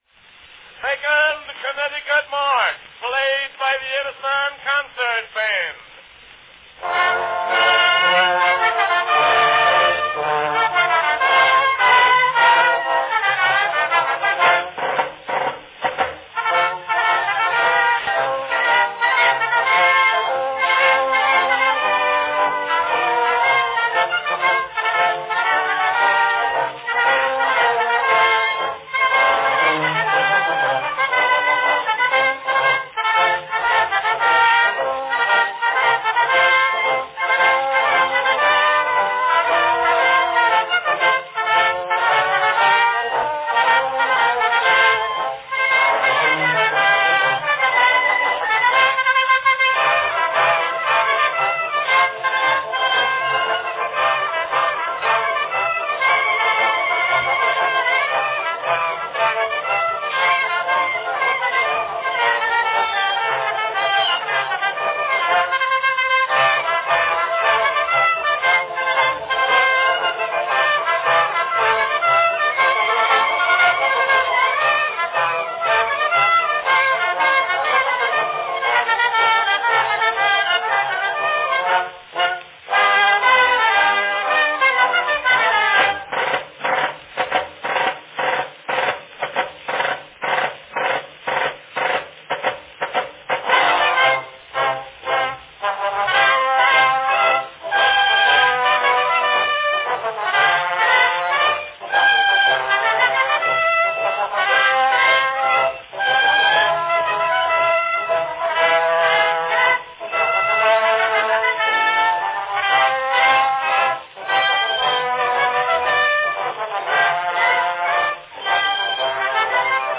Category Band